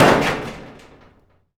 metal_med_impact_03.wav